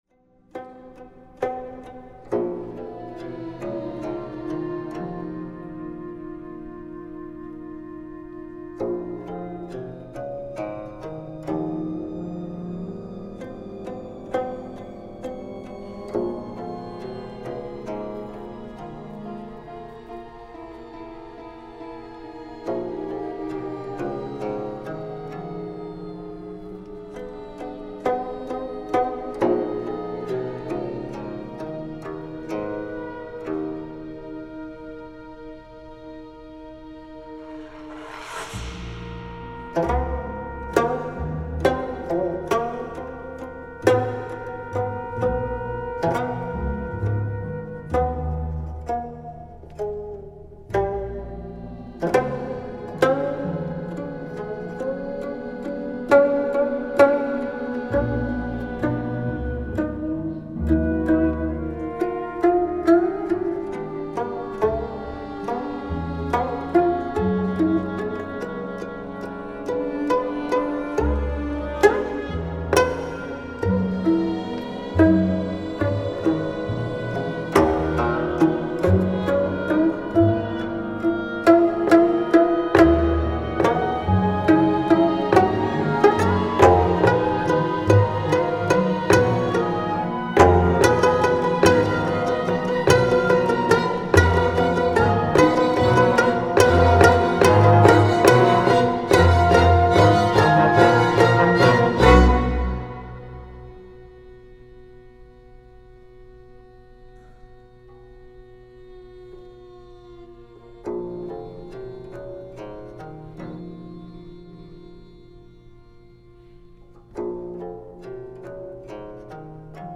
concerto for 9-string geomungo & orchestra